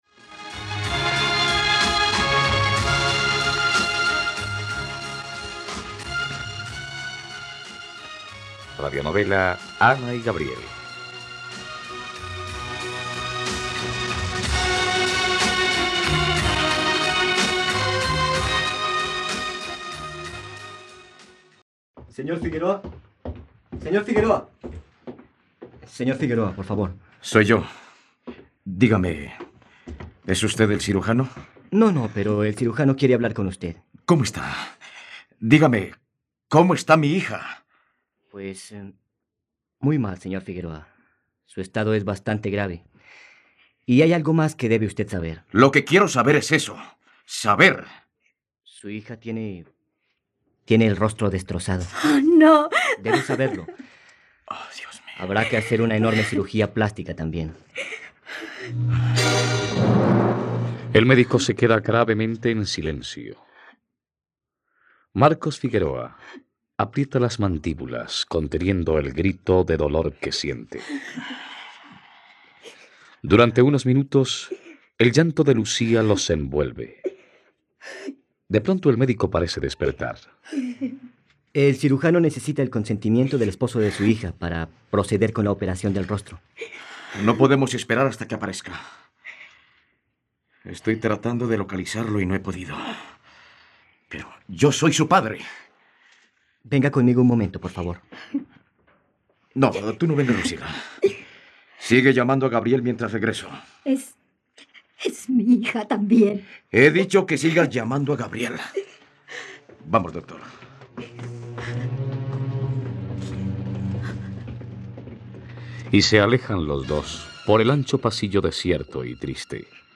..Radionovela. Escucha ahora el capítulo 47 de la historia de amor de Ana y Gabriel en la plataforma de streaming de los colombianos: RTVCPlay.